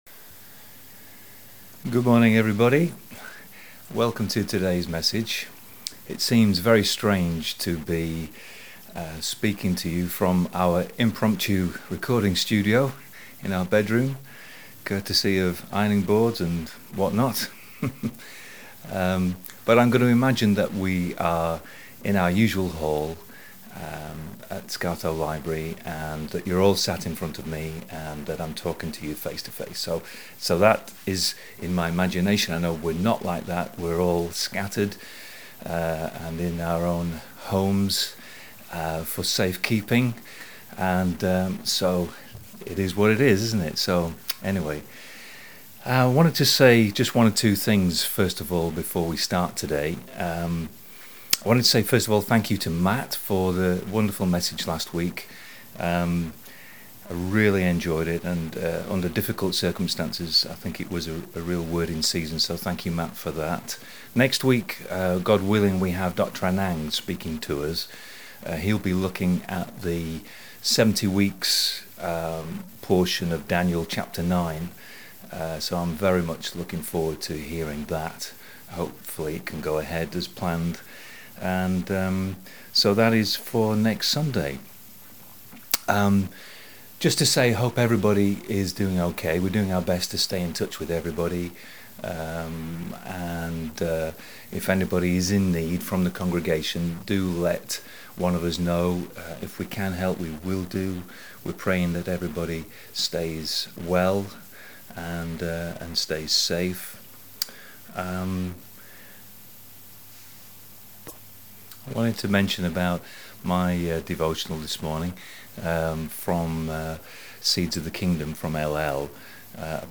29 March 2020 Good morning and welcome to our online message in place of today’s service.